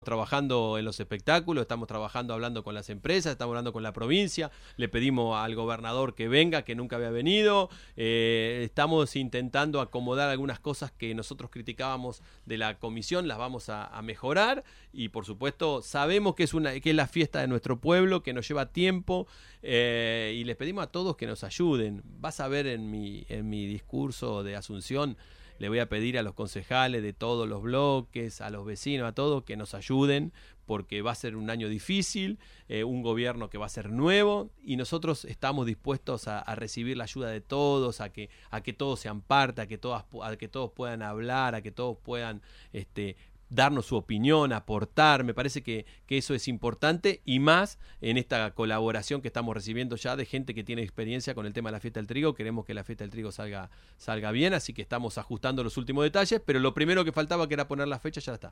En una visita a El Show de la Mañana, de FM 105, el mandatario que asumirá el domingo 10 ratificó que prevén invitar al gobernador Axel Kicillof a que visite la celebración.